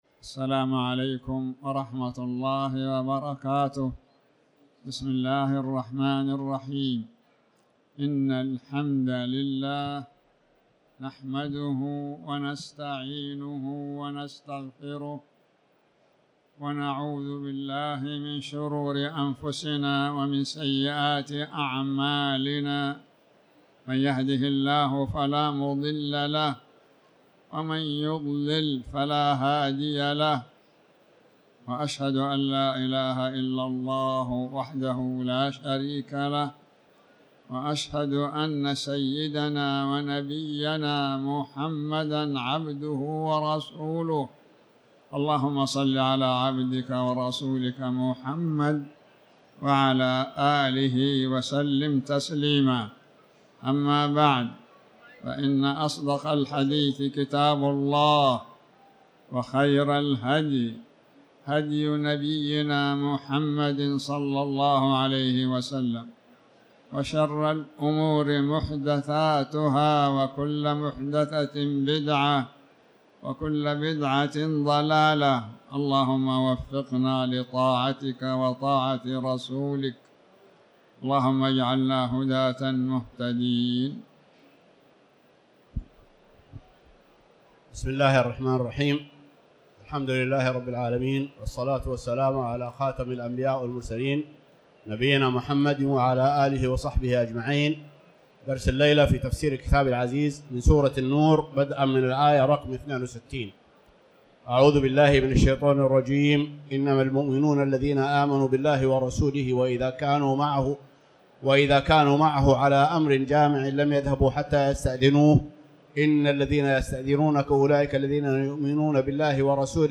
تاريخ النشر ٦ جمادى الآخرة ١٤٤٠ هـ المكان: المسجد الحرام الشيخ